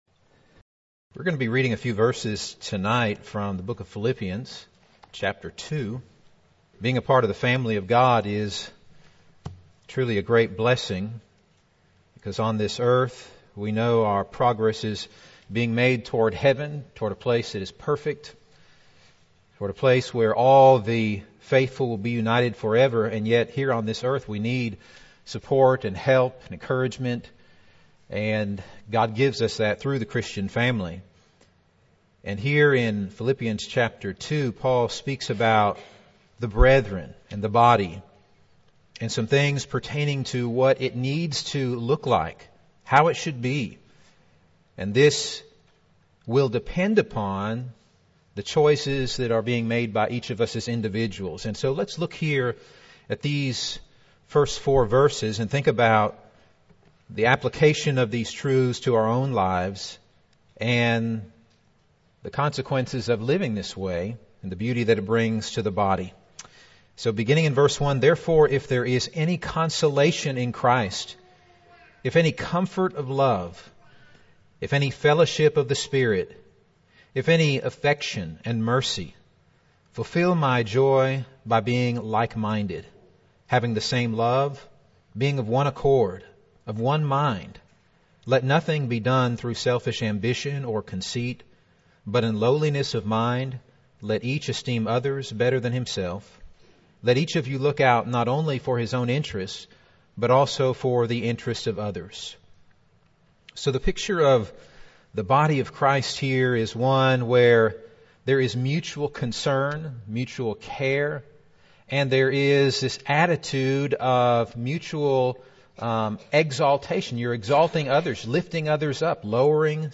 Midweek Bible Class « Young Men